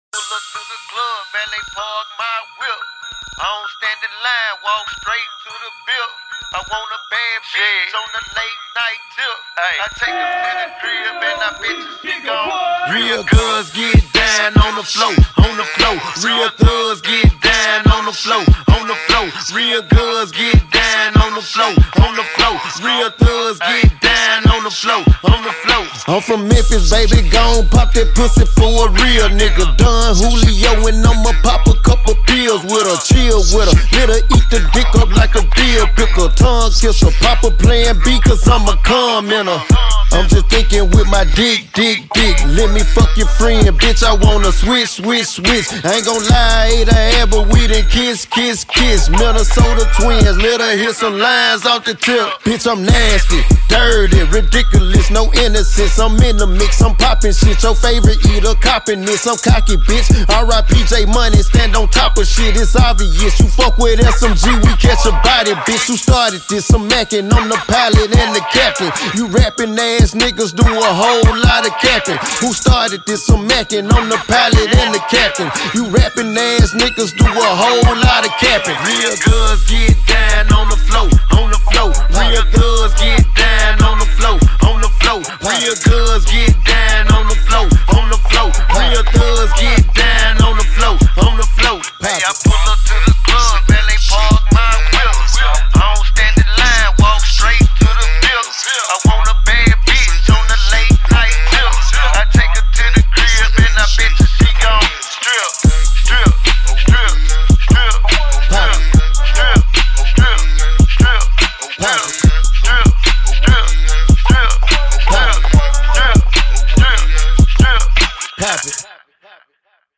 Hiphop
is a CLUB / PARTY ANTHEM record with a strong hook/bounce